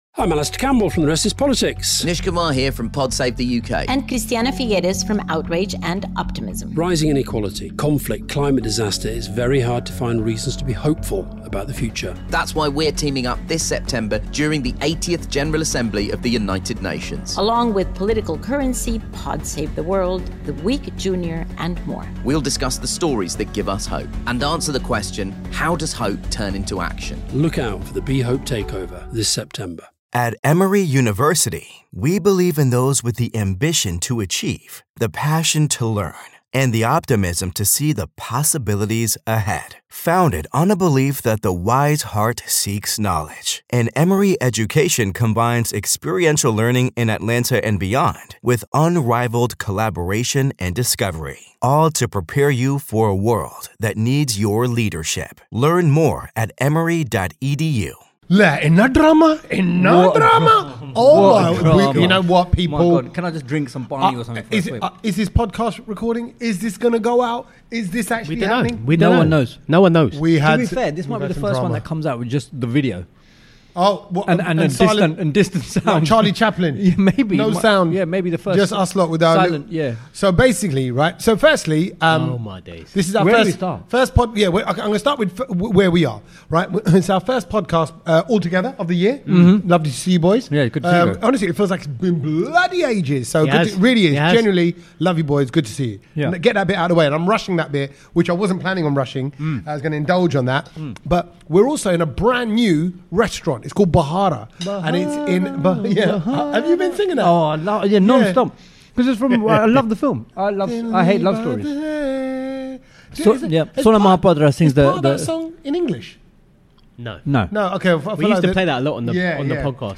Live from Bahara Indian restaurant in Brick lane!